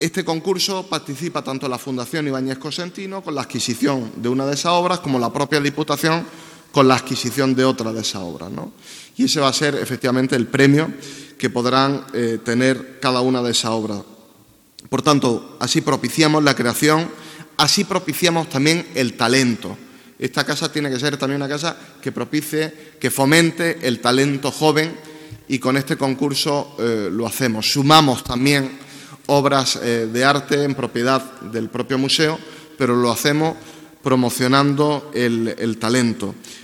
Esta mañana en el Salón de Actos del MUREC, en la antigua capilla del Hospital Provincial